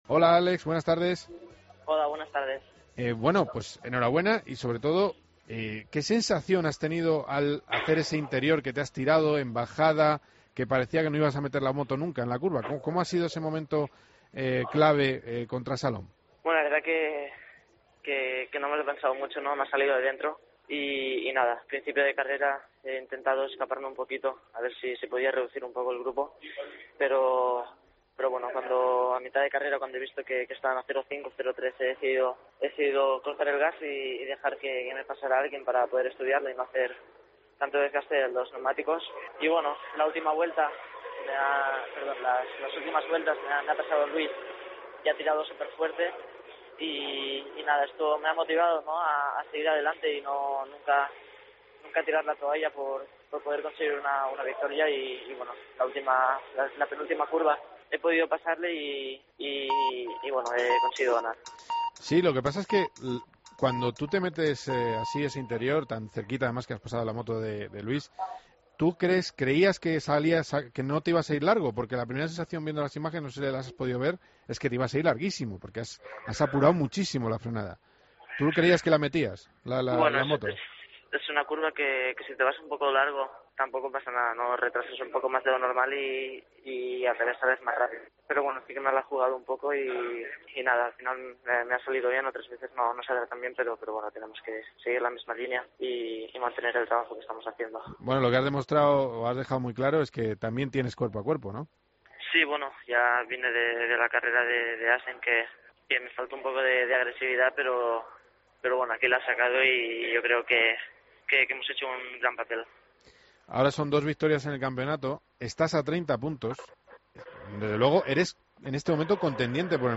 Hablamos con el piloto español tras imponerse en Moto3 en el GP de Alemania.